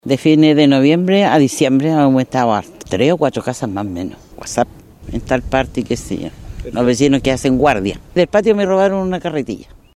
Otra vecina relató que son los propios vecinos quienes deben hacer rondas nocturnas por el sector. A través de WhatsApps se organizan para alertar la presencia de extraños en el sector.